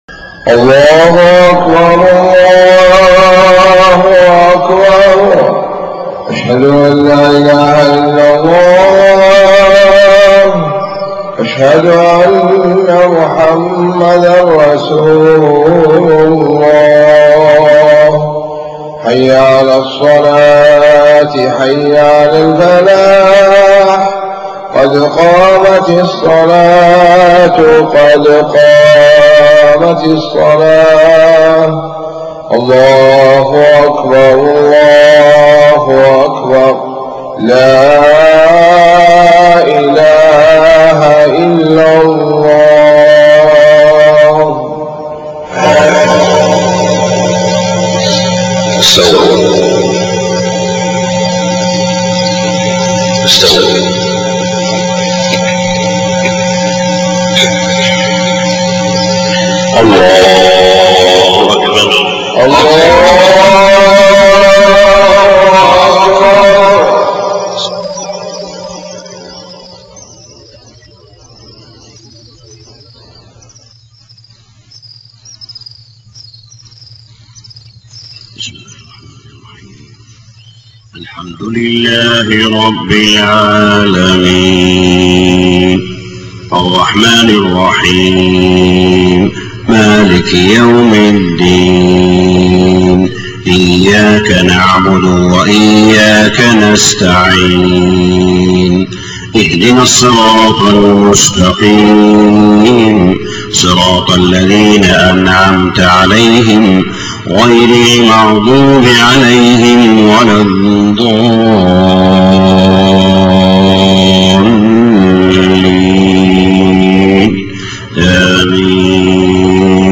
صلاة الفجر20 محرم 1430هـ خواتيم سورة هود 96-123 > 1430 🕋 > الفروض - تلاوات الحرمين